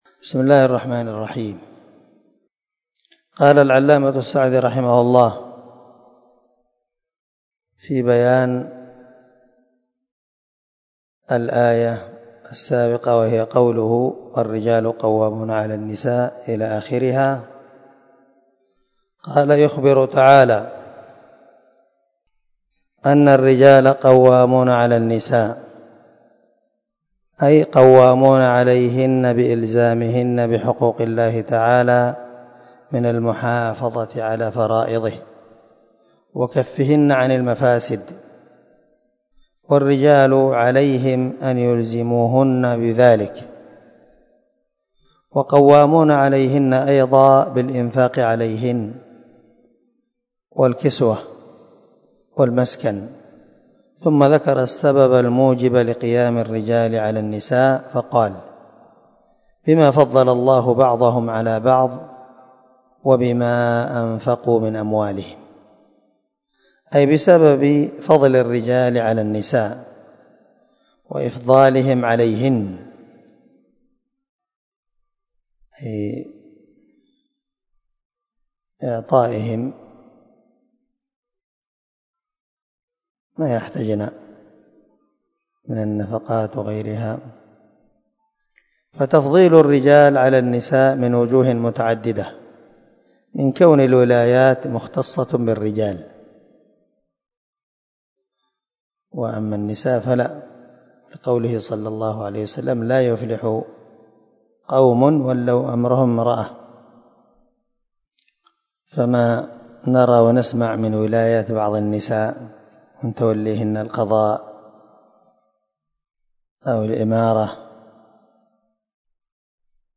259الدرس 27 تابع تفسير آية ( 34 ) من سورة النساء من تفسير القران الكريم مع قراءة لتفسير السعدي
دار الحديث- المَحاوِلة- الصبيحة.